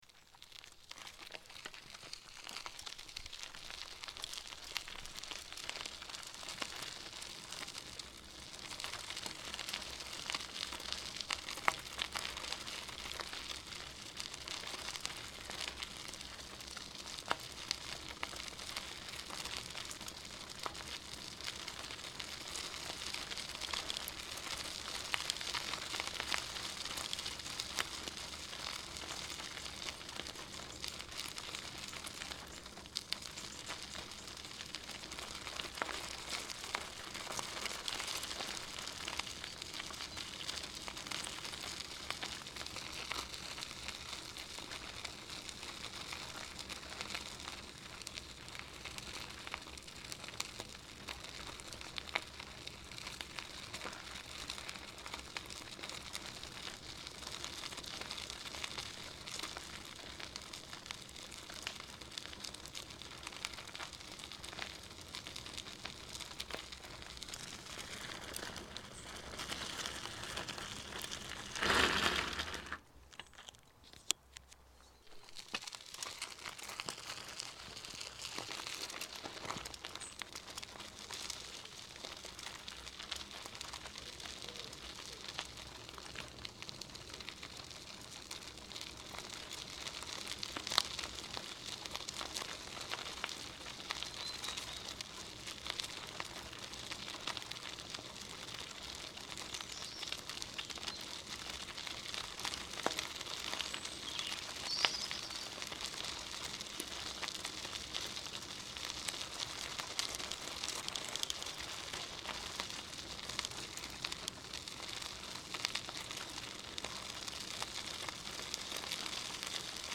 11. Езда на велосипеде по дороге грунтовой с торможением
ezda-na-velo-po-doroge.mp3